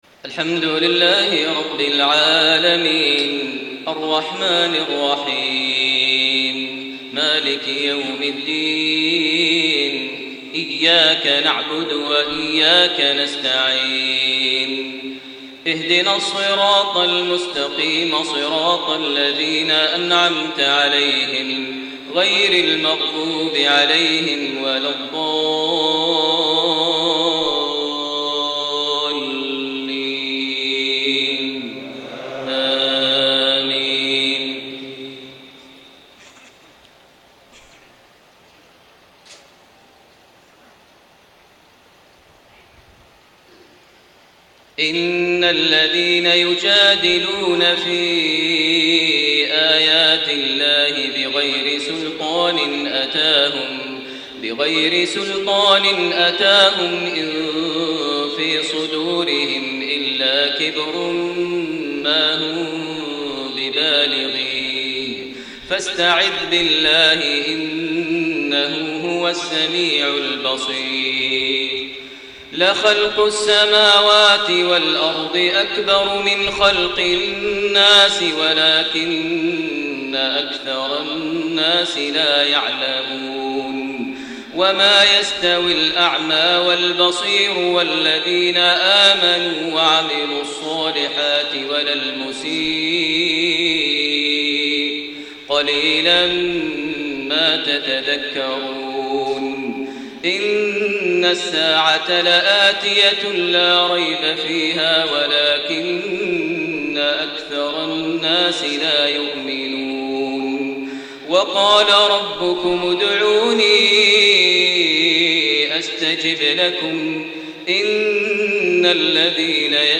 صلاة المغرب 2 شعبان 1433هـ من سورة غافر 56-65 > 1433 هـ > الفروض - تلاوات ماهر المعيقلي